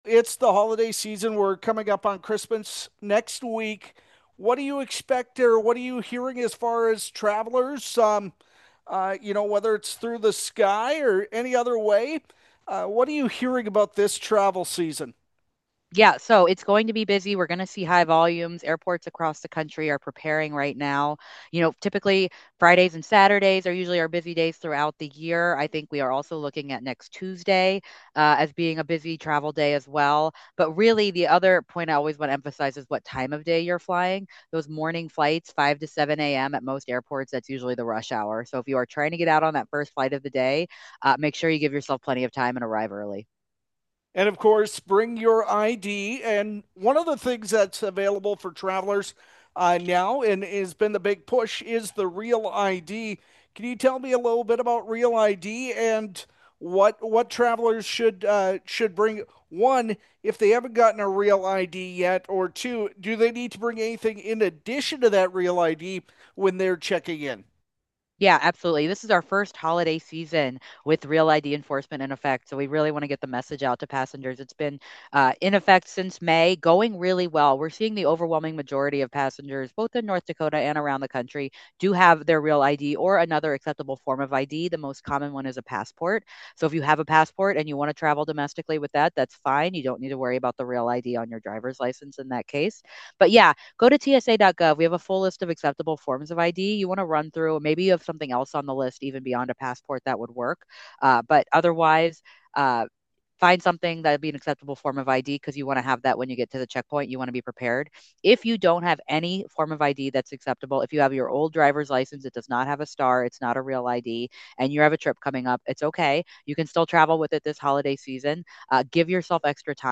Flag Family News Reporter